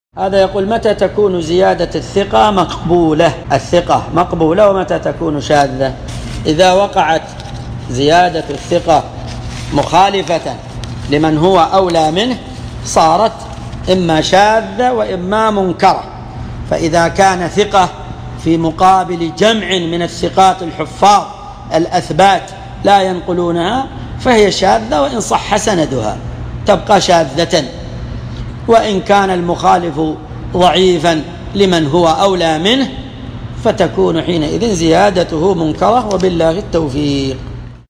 مقتطف من محاضرة بعنوان : (فضل الطاعة في شهر الله المحرم) .
ملف الفتوي الصوتي عدد الملفات المرفوعه : 1